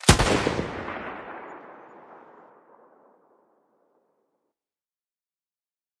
描述：用SM57麦克风录制在户外飞碟射击场。分享Audacity的深度和低音。
Tag: ADPP 战争 射击 军事 步枪 士兵 射击 武器 消防 军队 战斗 战争 射击 手枪